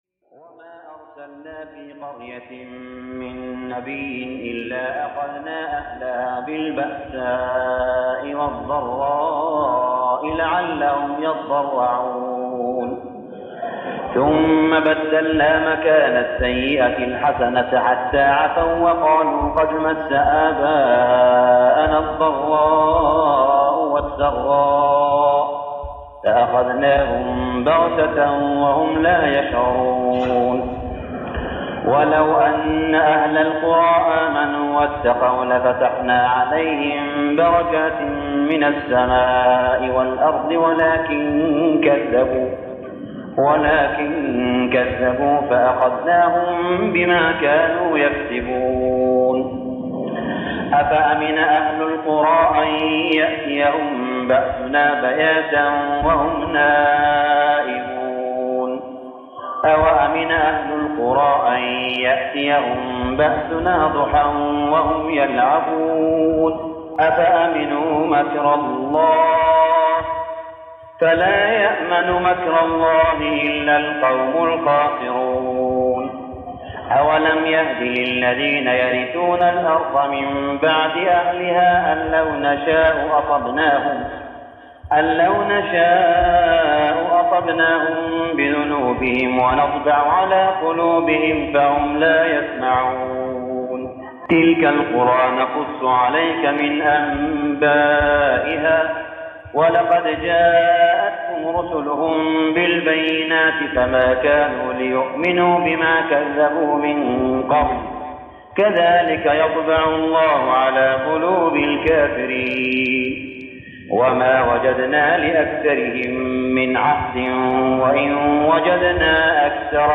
صلاة التراويح ليلة 9-9-1411هـ سورة الأعراف 94-171 | Tarawih Prayer night 9-9-1411AH Surah Al-A'raf > تراويح الحرم المكي عام 1411 🕋 > التراويح - تلاوات الحرمين